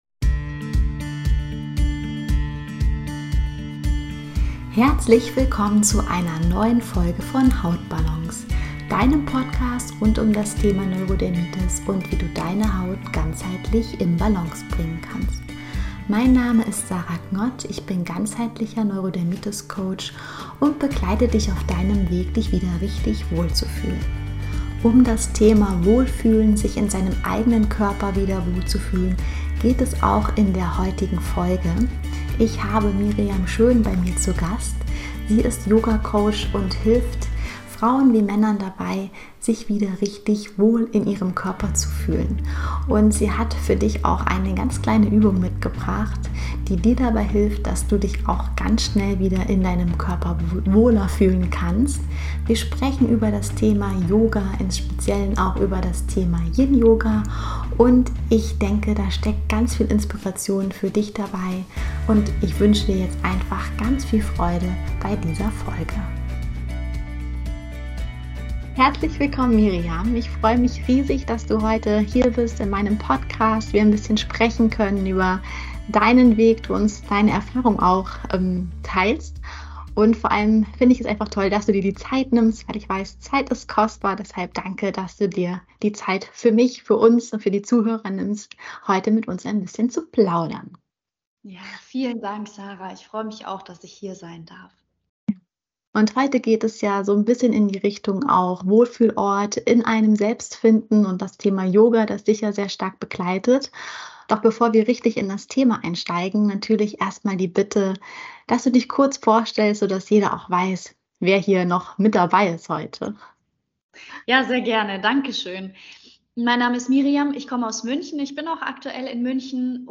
Finde deinen inneren Wohlfühlort - Interview